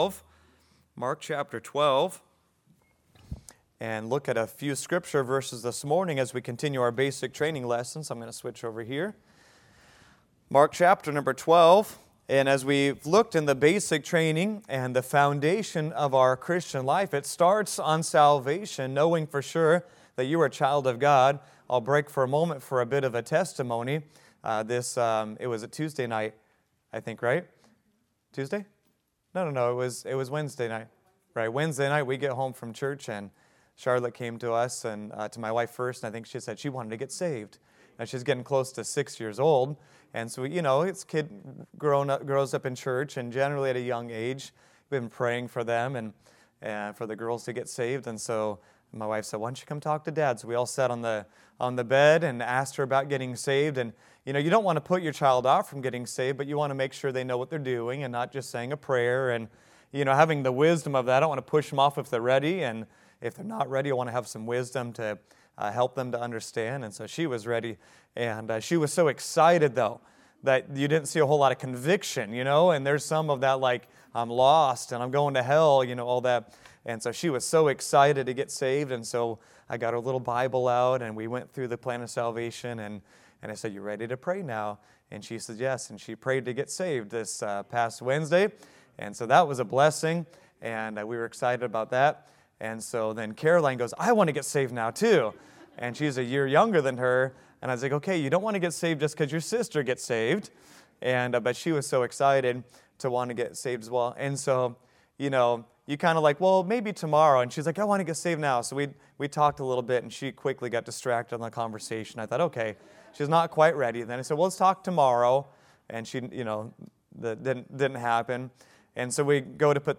Stewardship | Sunday School